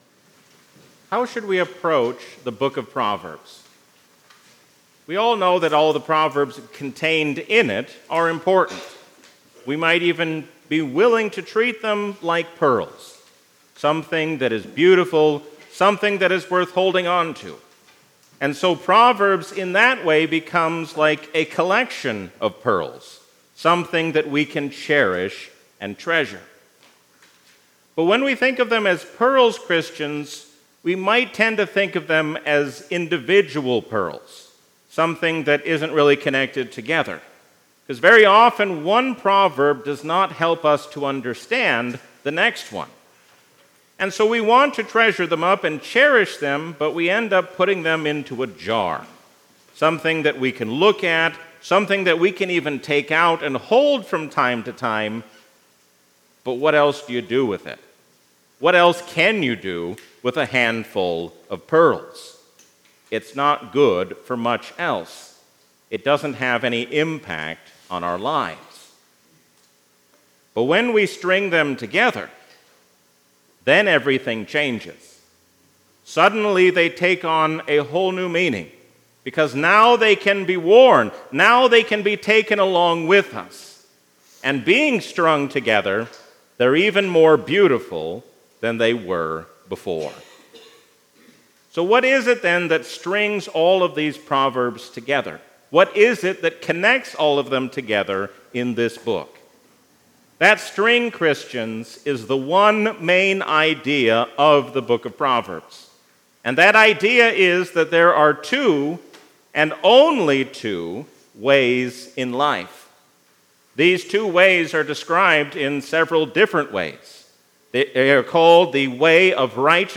A sermon from the season "Trinity 2024."